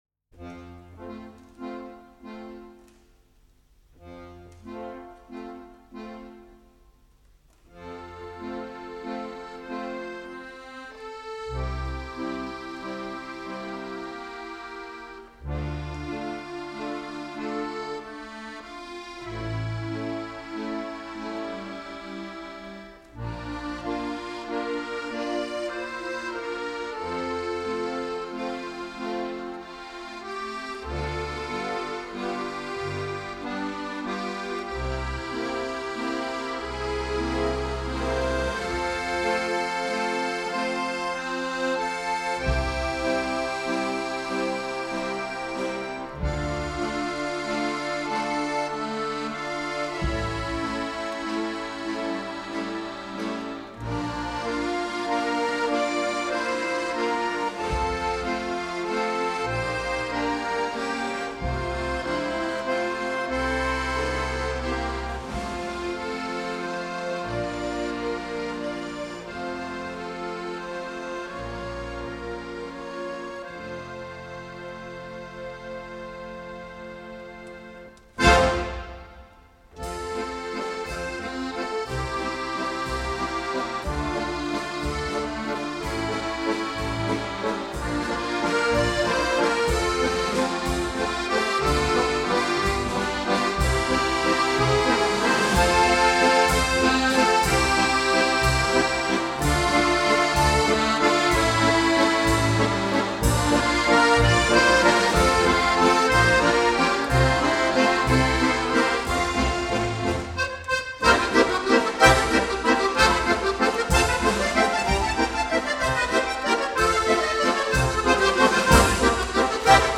Akkordeon Solo
Konzertwalzer